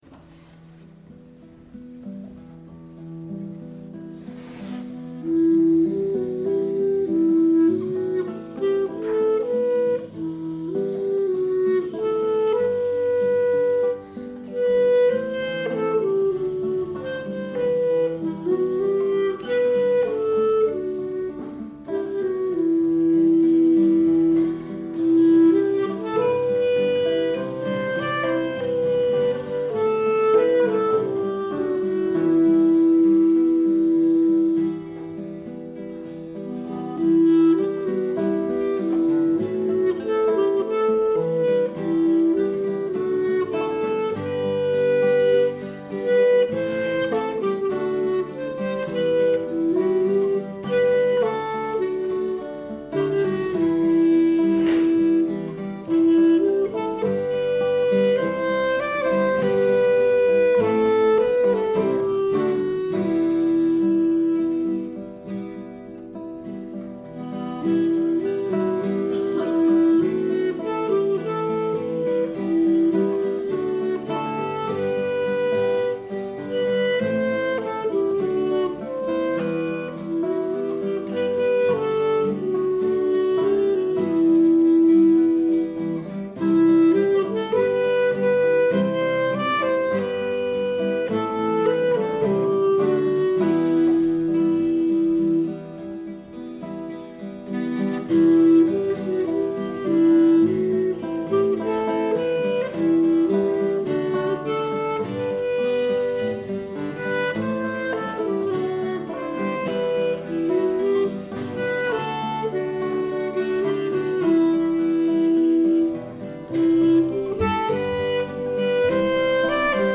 Sunday Instrumental